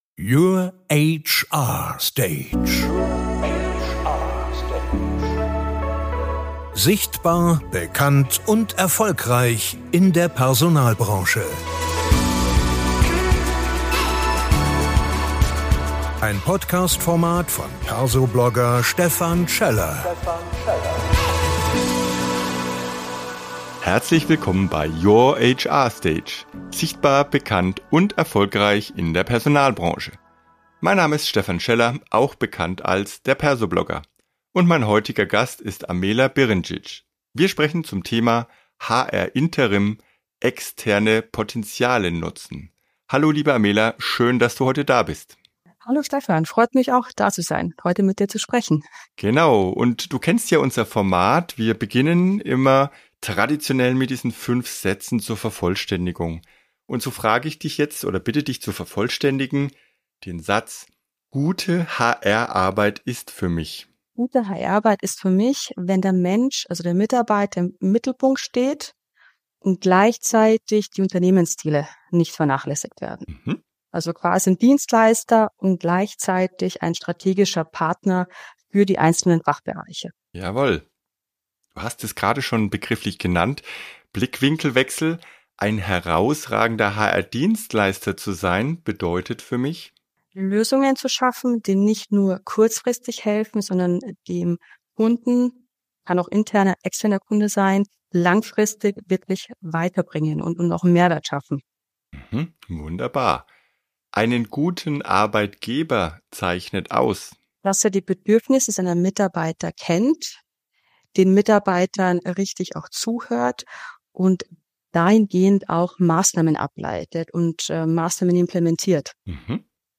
Ein inspirierendes Gespräch für alle, die HR-Arbeit flexibel, innovativ und zukunftsorientiert denken.